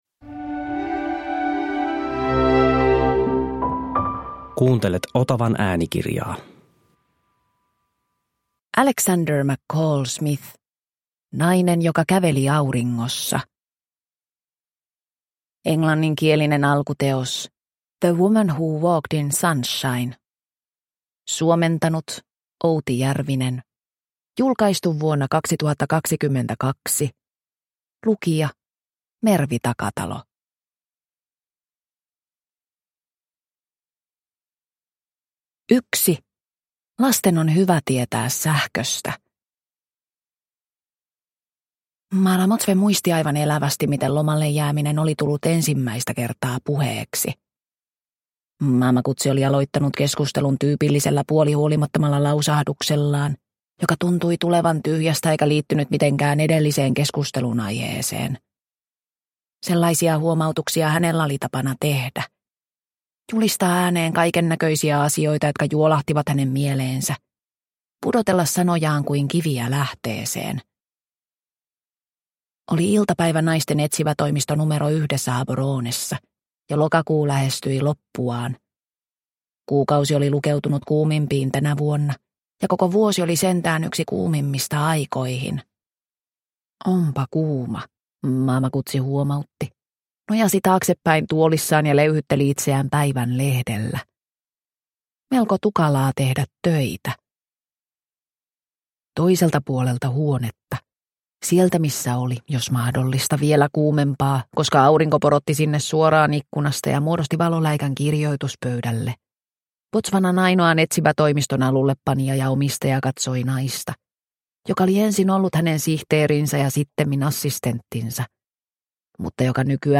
Nainen joka käveli auringossa – Ljudbok – Laddas ner